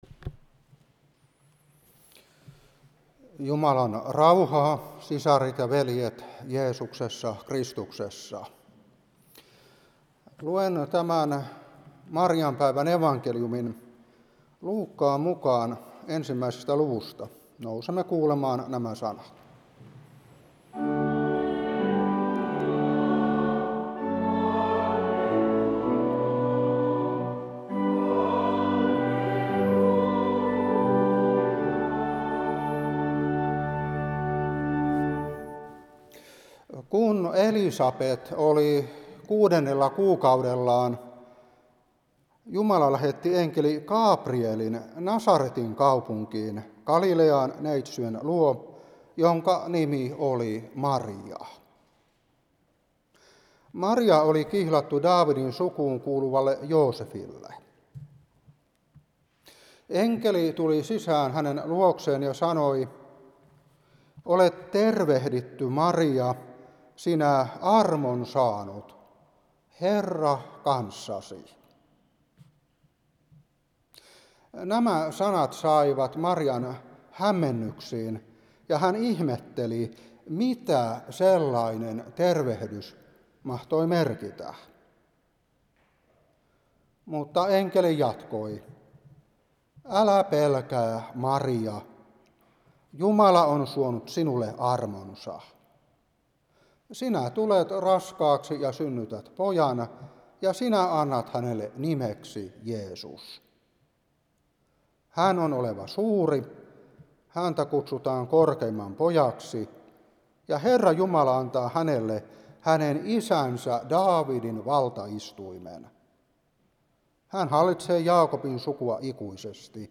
Saarna 2025-3.
Alahärmä